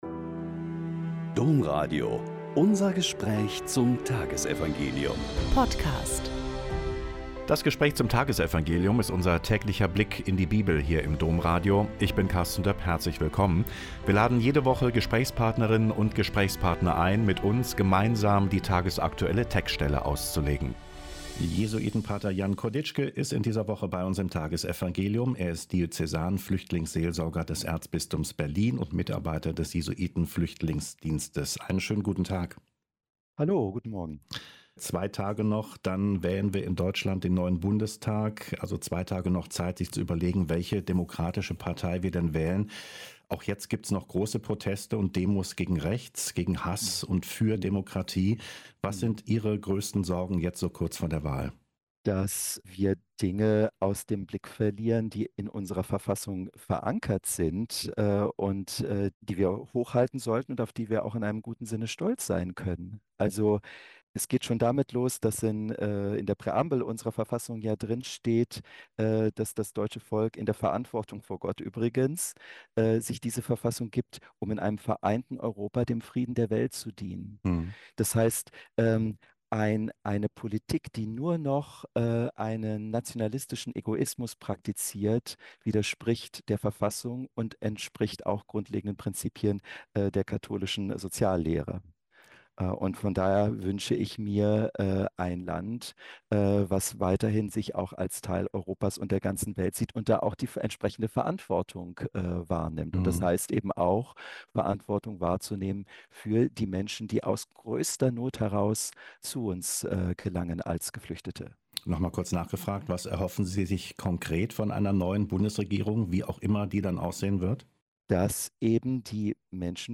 Mk 8,34-9,1 - Gespräch